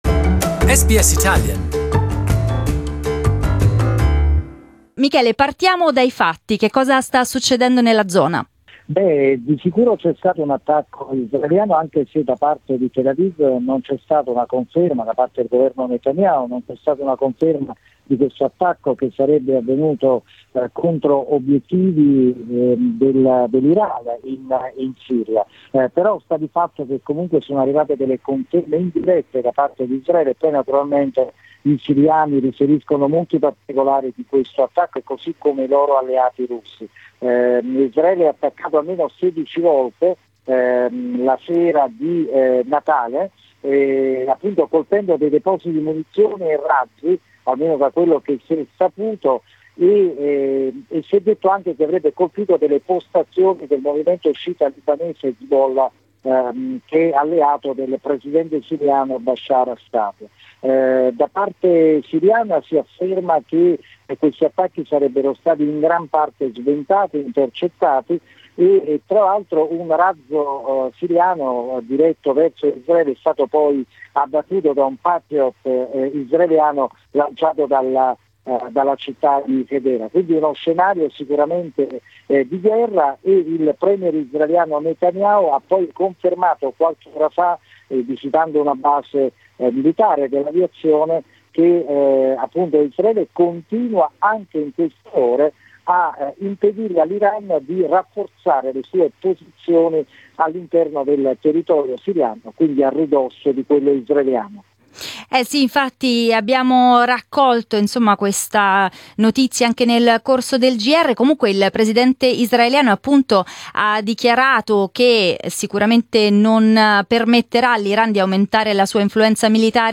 We talked about it with our Middle East correspondent